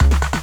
5 Harsh Realm Distorted Kick Clatter.wav